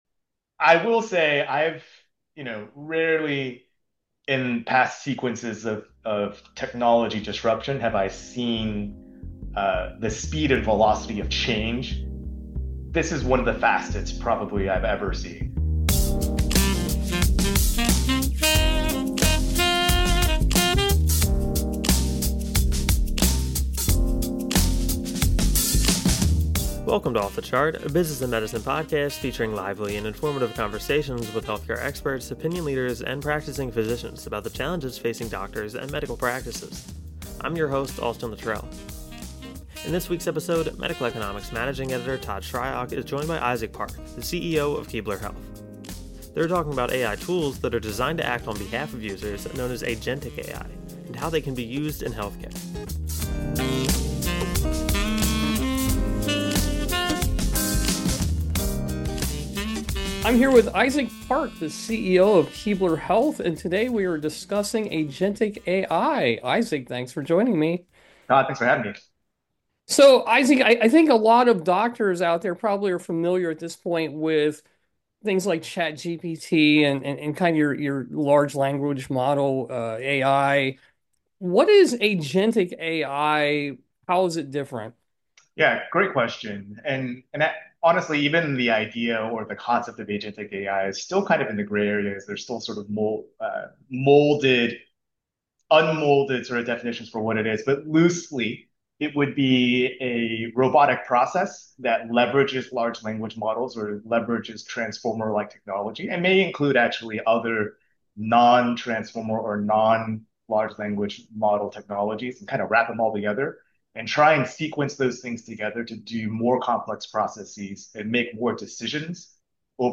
Off the Chart: A Business of Medicine Podcast features lively and informative conversations with health care experts, opinion leaders, and practicing physicians about the challenges facing medical practices today.